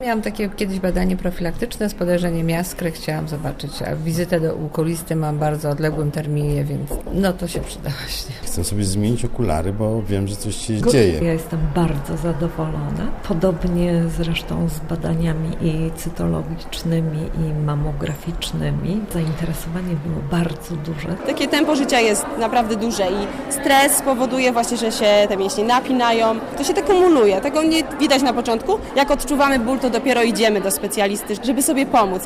Posłuchaj, co mówili Poznaniacy, którzy przyszli do urzędu wojewódzkiego: